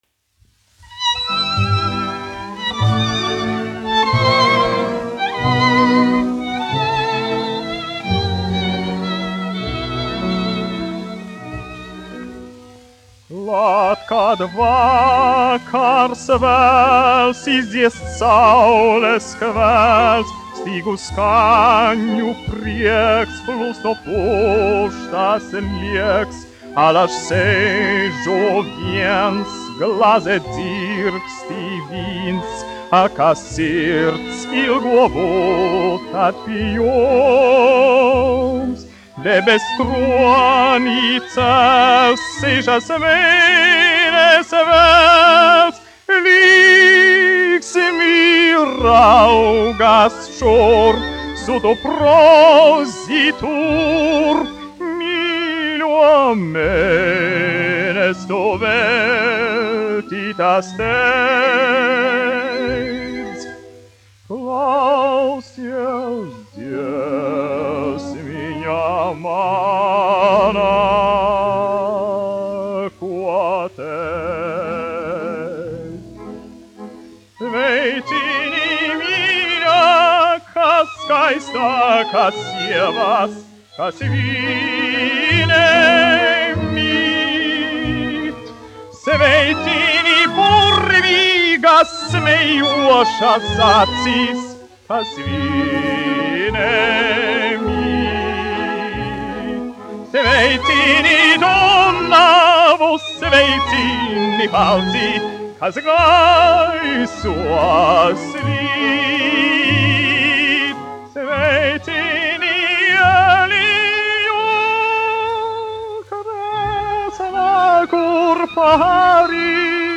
1 skpl. : analogs, 78 apgr/min, mono ; 25 cm
Operetes--Fragmenti
Skaņuplate